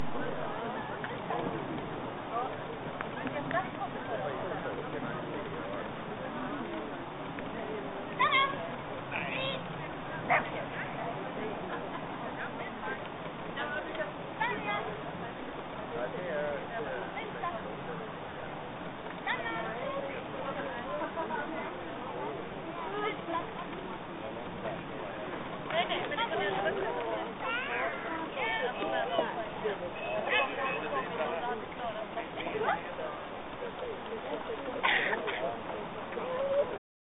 Tävlingen gick av stapeln på Norrköpings Brukshundklubb.
Gnyendet i bakgrunden kommer från Chili, som inte alls ville ligga stilla hos husse när Kenzo och jag var inne på planen...